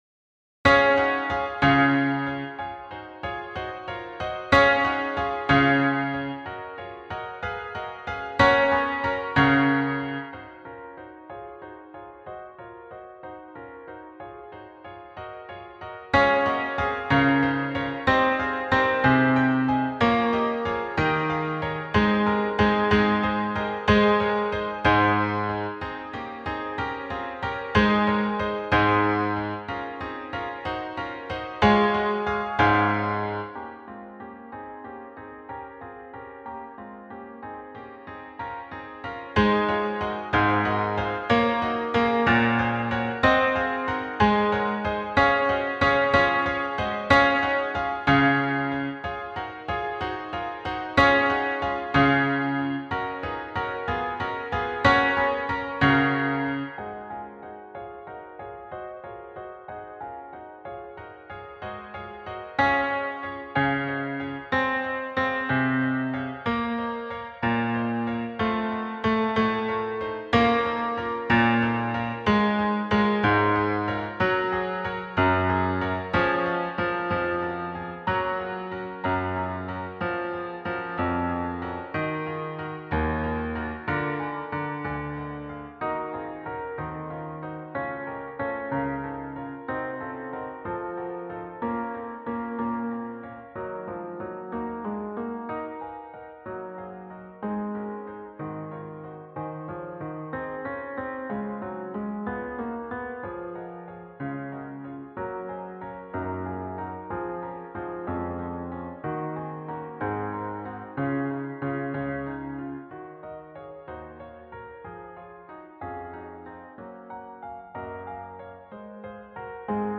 Bach-Hmoll-22-Sanctus-B.mp3